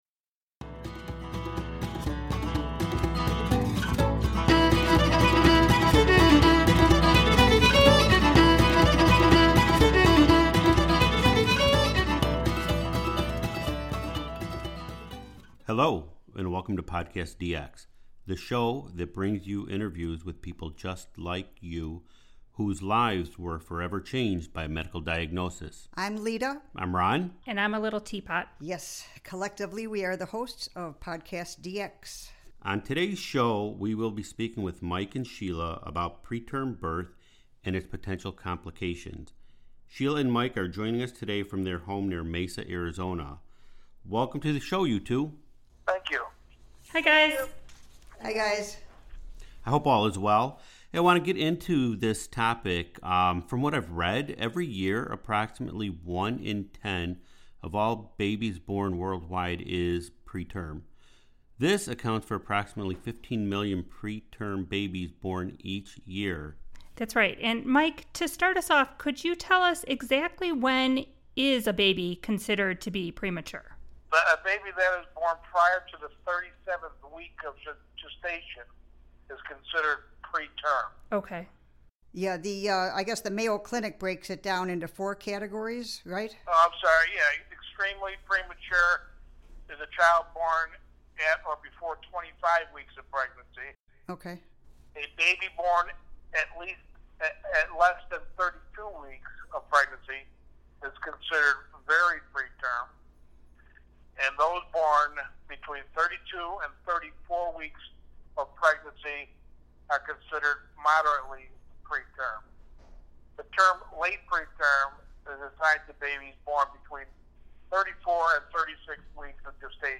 In this week's episode we have the honor of interviewing two of societies real life hero's?